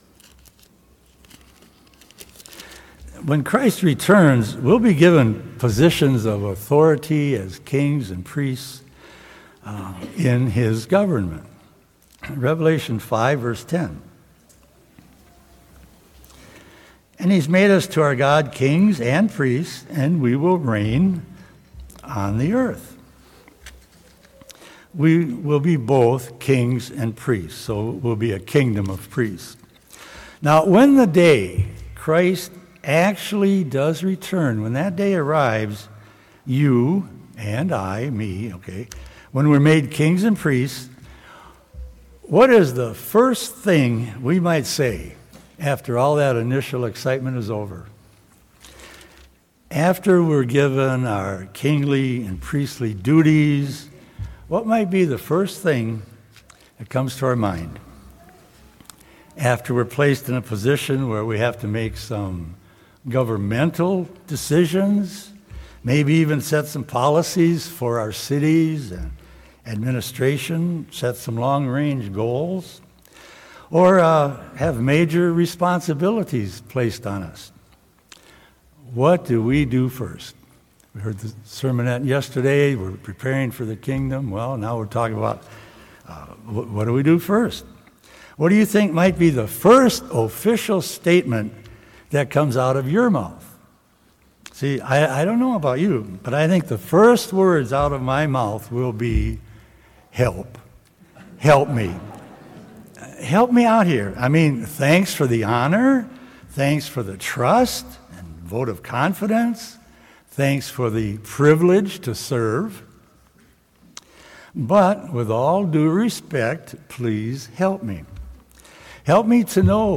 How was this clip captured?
Given in Lake Geneva, Wisconsin